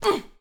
SFX_Battle_Vesna_Defense_10.wav